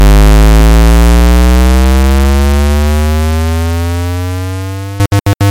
普通科幻 " weirdscifinoise
描述：一个奇怪的，基于科幻的噪音。
标签： 机电 未来 行星 怪异 网络连接 另一个-p lanet 科幻 未来 小说 科幻小说 未被发现的 恐怖 科学 空间 外星人 科幻 科幻 生活 编辑 神秘
声道立体声